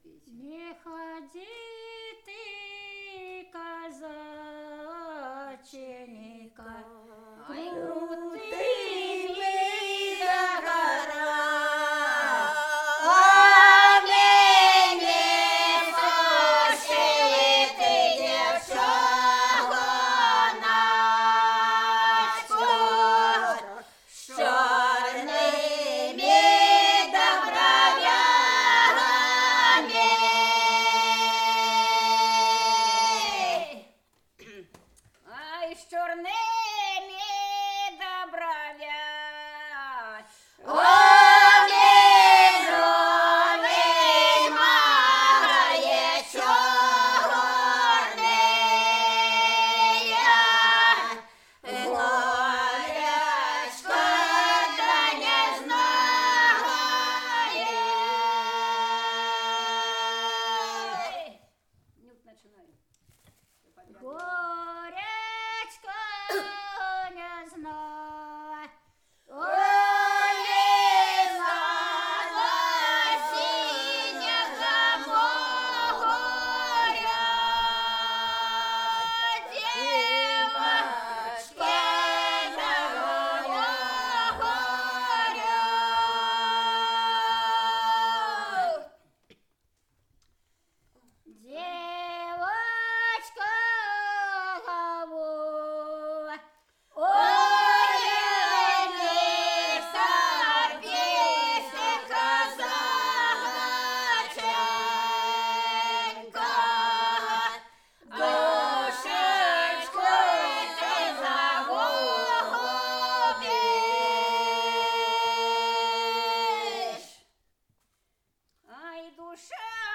01 Лирическая песня «Не ходи ты, казаченька, крутыми горами» в исполнении ансамбля «Лопатенские казачки» с. Лопатни Клинцовского р-на Брянской обл.